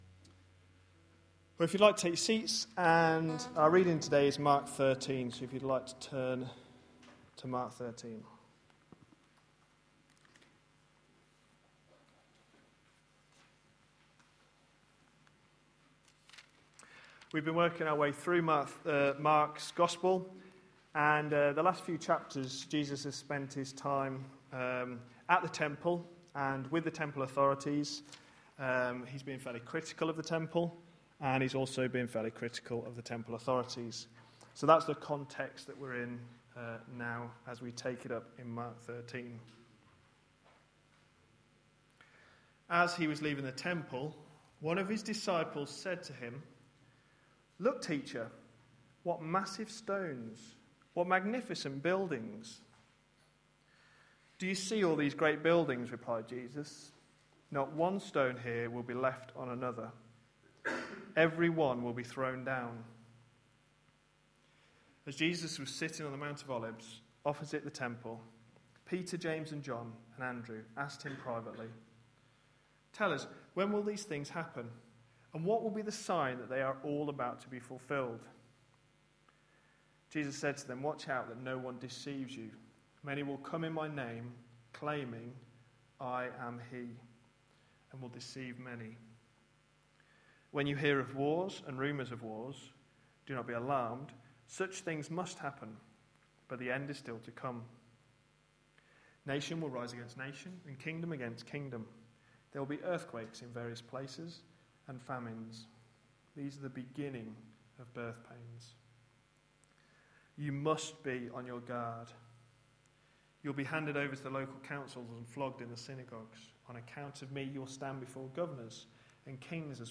A sermon preached on 18th March, 2012, as part of our Mark series.